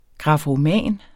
Udtale [ gʁɑfoˈmæˀn ]